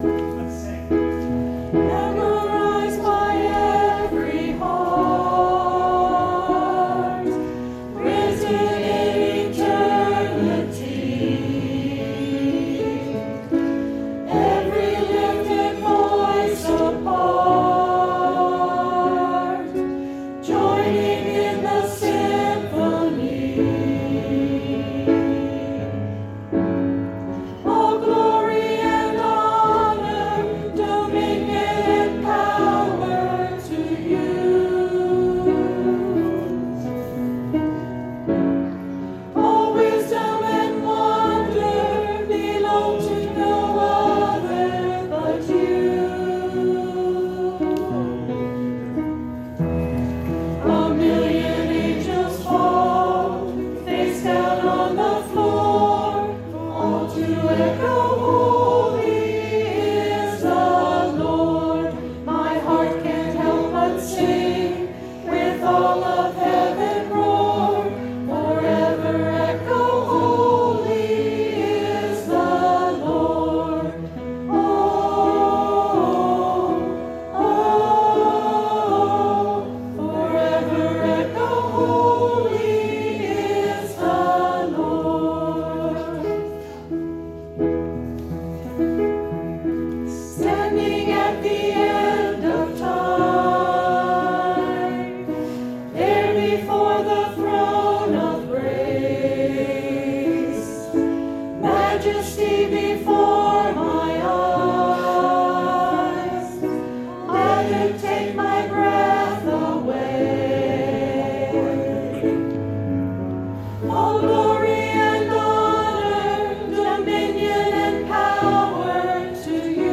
Canticle of Praise "Echo Holy"